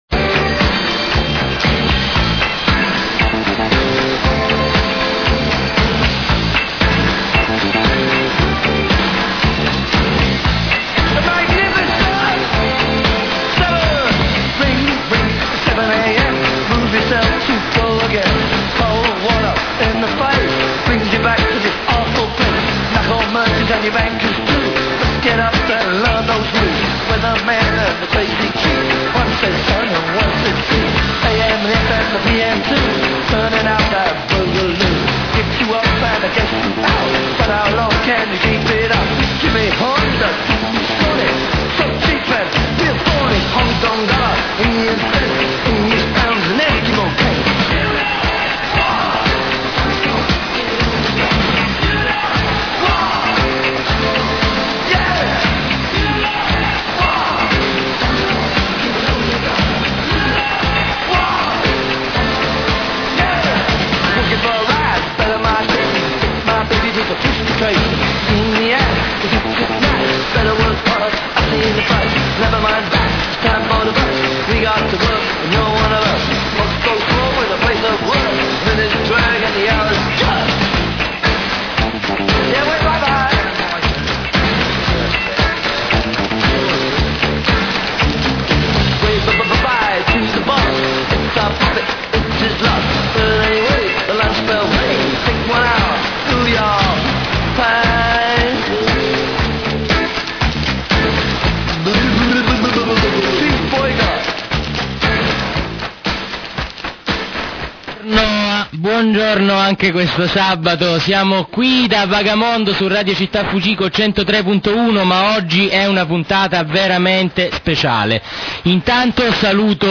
Vag61 - Scarica la puntata del 12 maggio '07 - Dopo le durissime dichiarazioni dell'assessore Santandrea contro Vag61 e i centri sociali, puntata speciale dedicata all'ultimo attacco cofferatiano a chi immagina e pratica una città altra. Rassegna stampa, dichiarazioni, commenti politici e solidarietà, ma anche interventi in diretta telefonica degli ascoltatori. Ma soprattutto una pernacchia che è già nella leggenda...